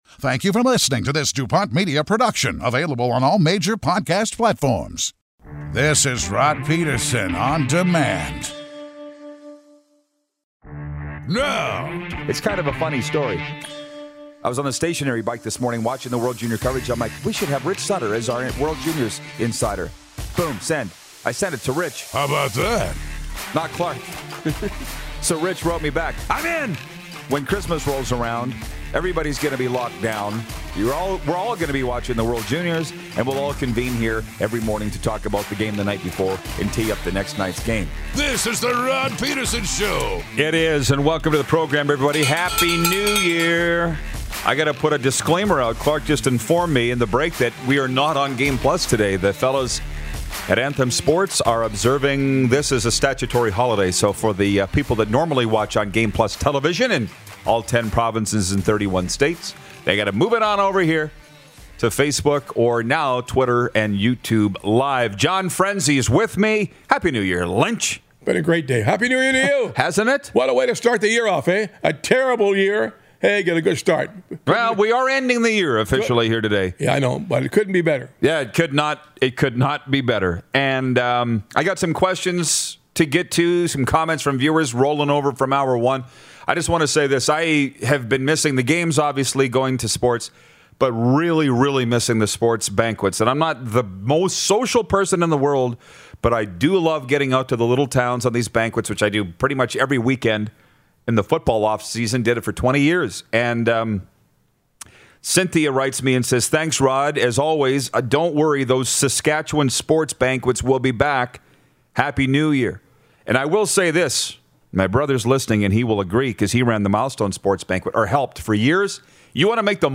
We wrap it up with a surprise visit from MLB great, Gregg Zaun!